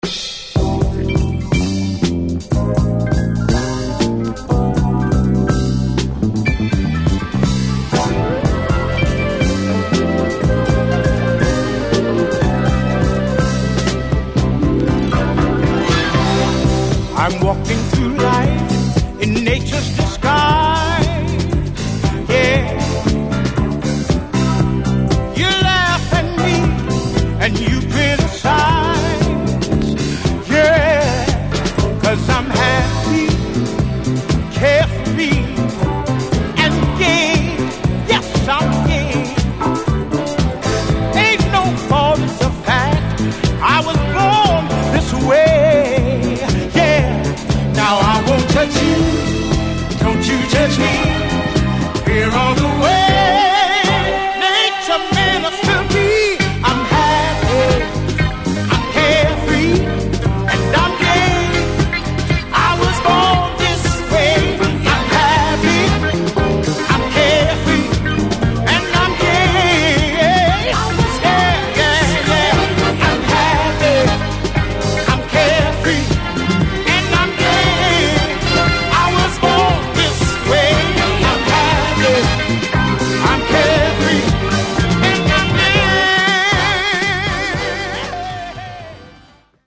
Disco~Garage Classic!!